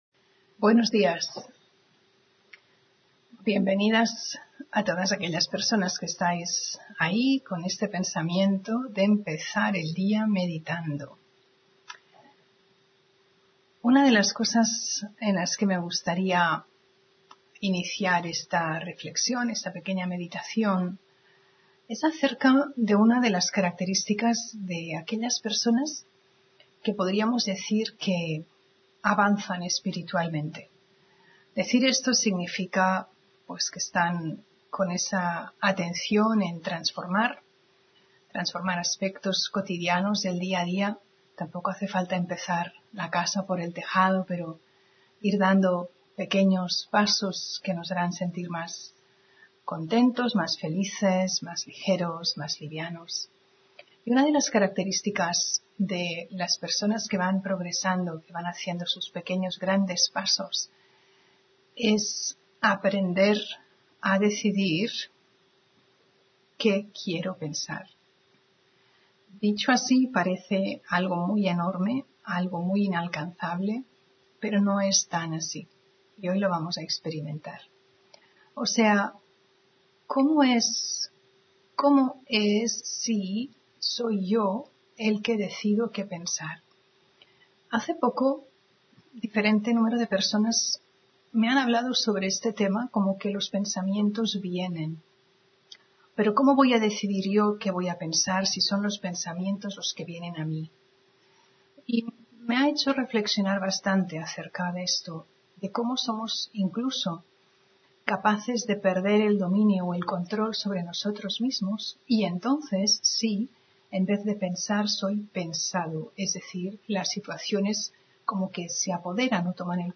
Meditación y conferencia: La practica de ser un observador (29 Octubre 2025)